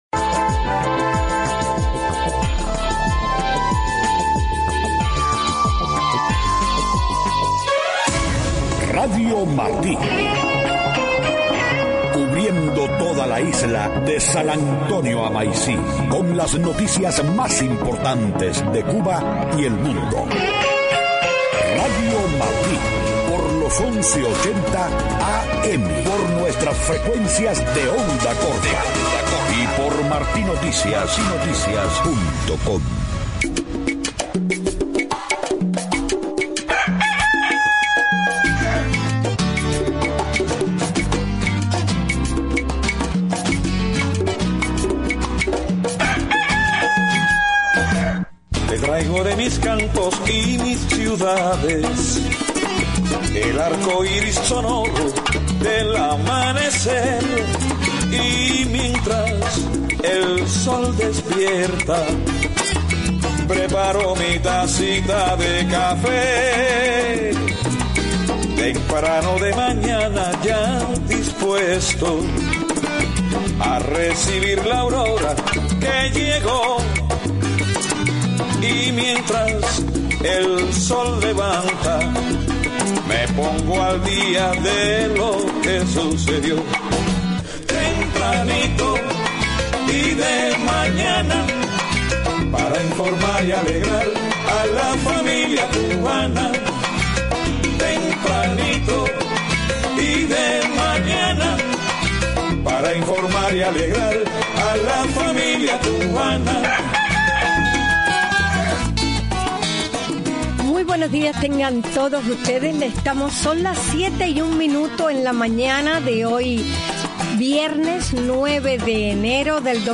7:00 a.m Noticias: Gobierno de Cuba pone en libertad a más de 30 presos políticos y se esperan más liberaciones para hoy. Próxima Cumbre de las Américas en Panamá será reto y oportunidad para sociedad civil cubana.